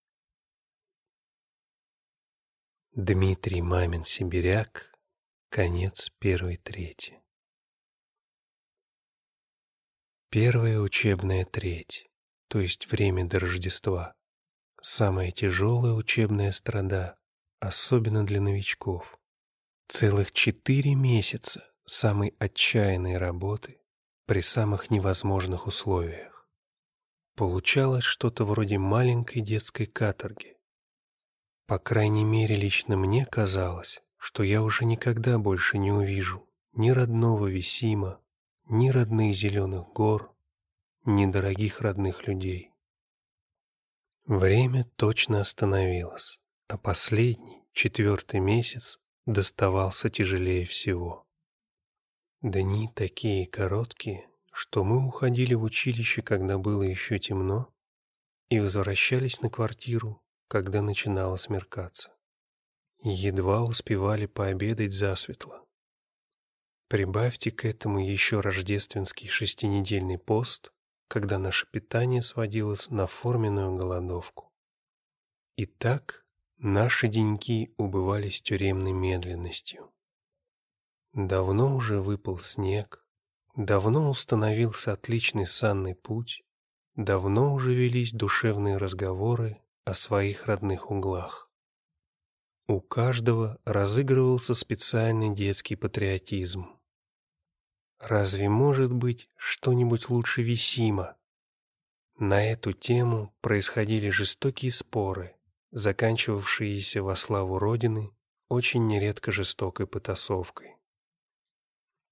Аудиокнига Конец первой трети | Библиотека аудиокниг
Прослушать и бесплатно скачать фрагмент аудиокниги